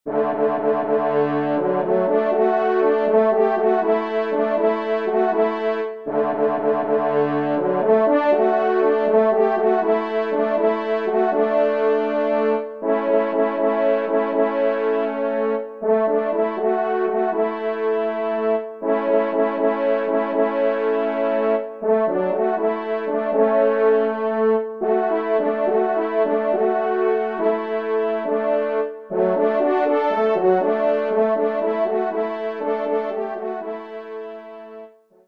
Genre :  Divertissement pour Trompes ou Cors en Ré
3eTrompe